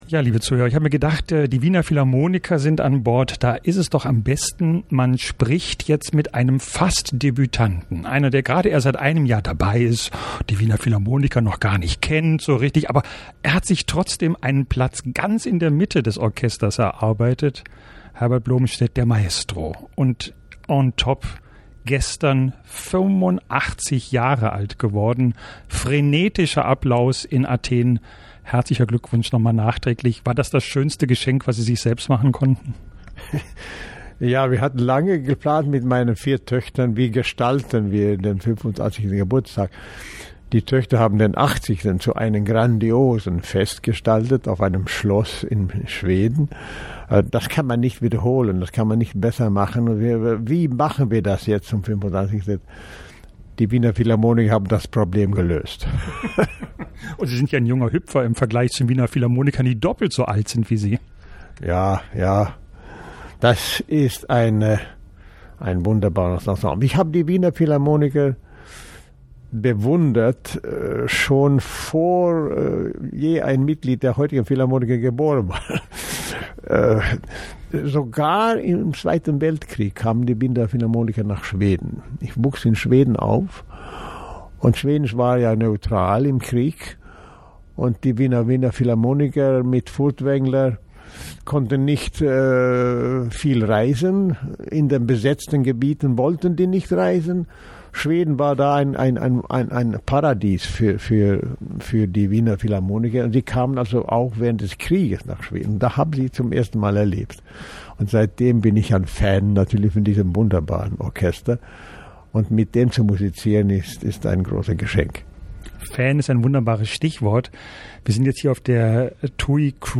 Reiseradio_109_Interview_Herbert_Blomstedt.mp3